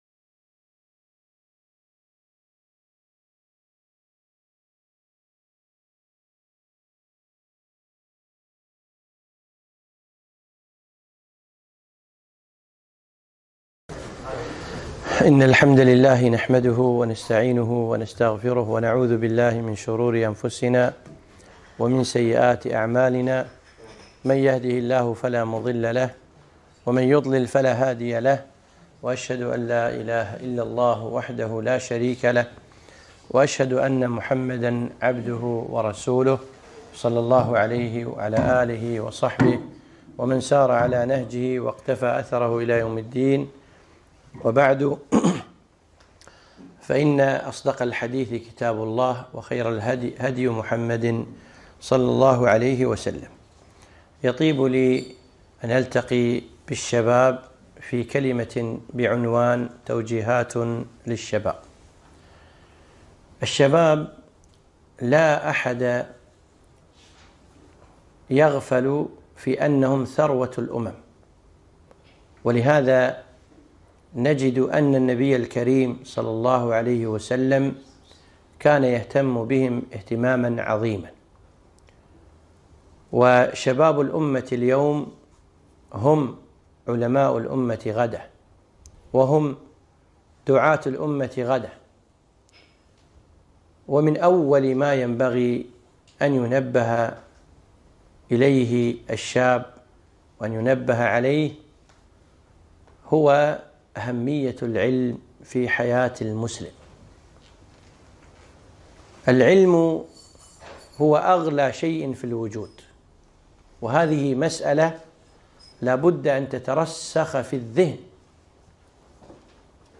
محاضرة - توجيهات للشباب